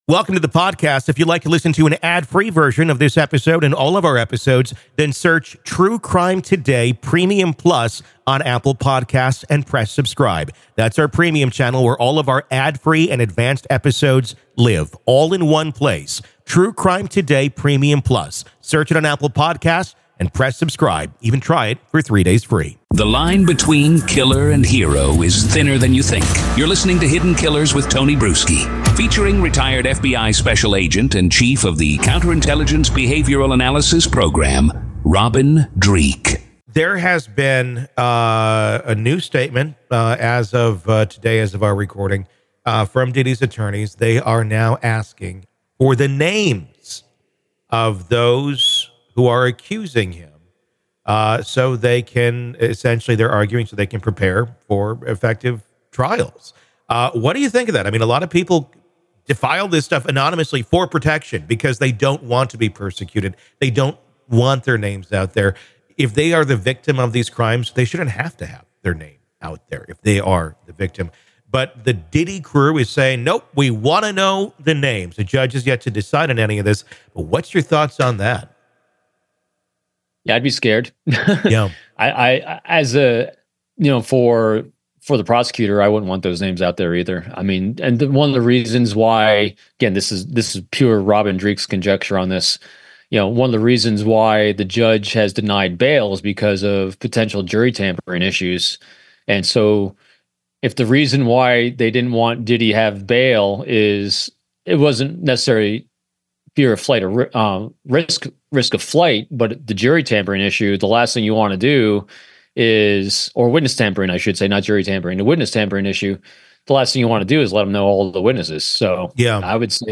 From allegations of witness tampering to the hidden shame tied to participation in questionable events, this discussion highlights the murky line between victimhood and complicity.